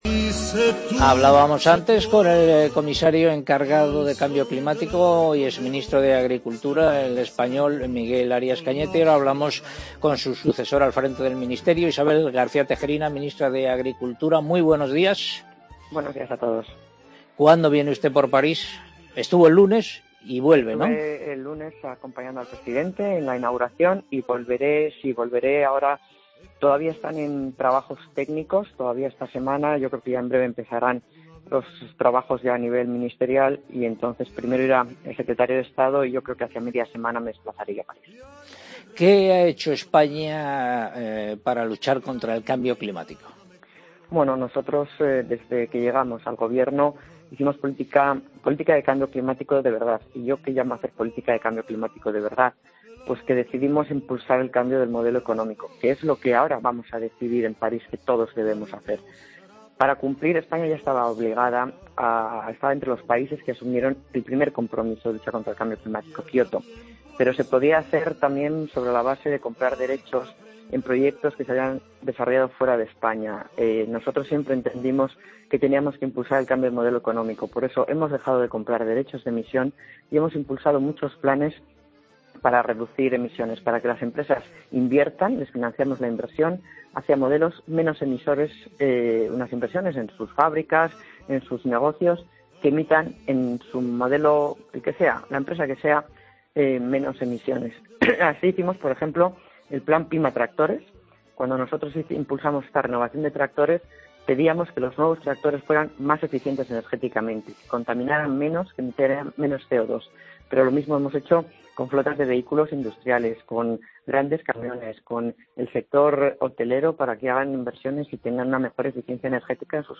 Escucha la entrevista a la ministra de Agriultura, Alimentación y Medio Ambiente, Isabel García Tejerina en Agropopular